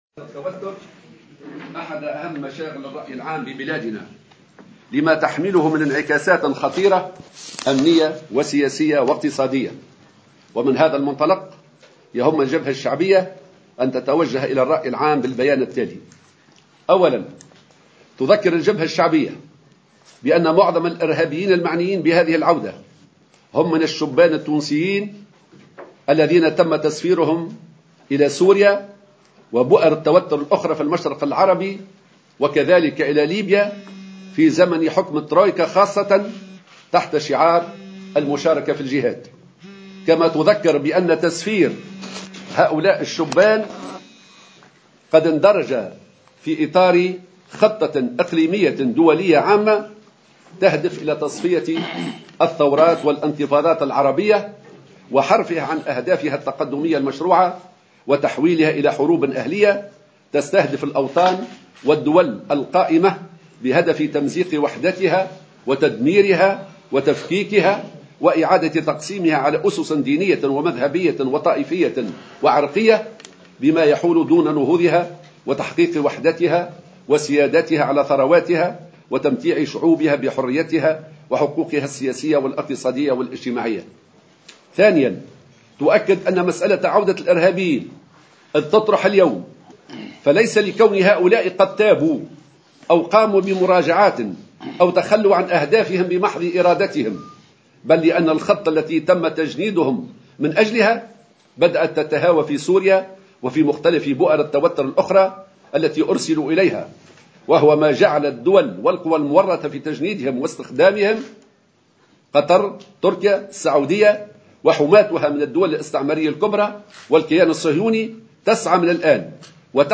دعت الجبهة الشعبية إلى تنظيم المؤتمر الوطني لمقاومة العنف والإرهاب في أقرب وقت، لما له من أهمية في التعامل مع الإرهابيين العائدين من بؤر التوتر، وفق ما ورد على لسان ناطقها الرسمي حمّة الهمّامي خلال ندوة صحفية نظمتها الجبهة اليوم في تصريح لمراسل الجوهرة اف ام.